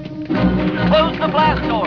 When the Stormtroopers are chasing Han one of them now says